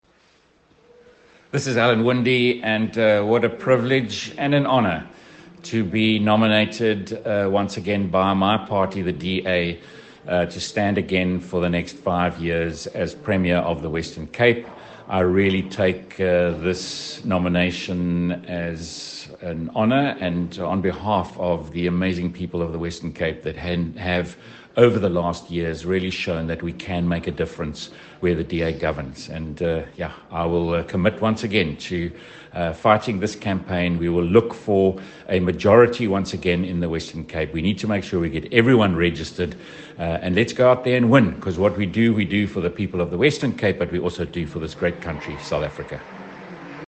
soundbite by Alan Winde.